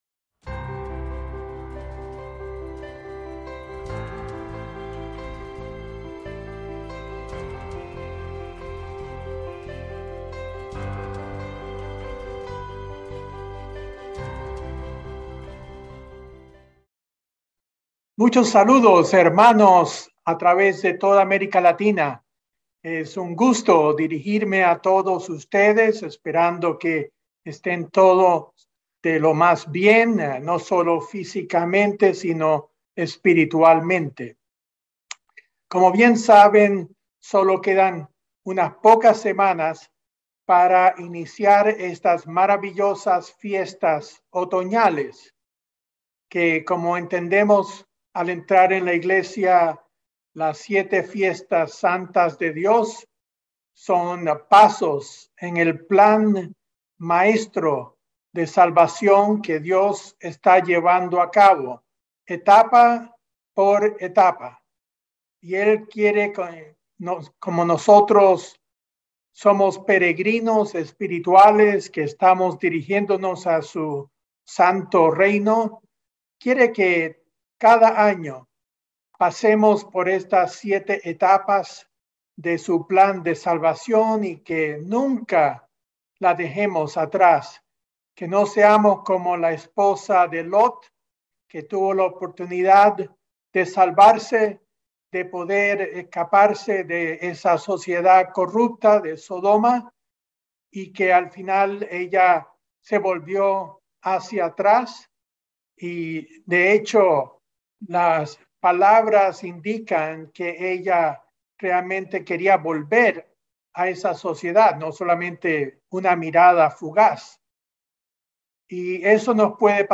El reciente descubrimiento de las posibles ruinas de Sodoma nos lleva a preguntarnos ¿qué podemos aprender de esta advertencia de Dios para su pueblo? Mensaje entregado el 17 de septiembre de 2022.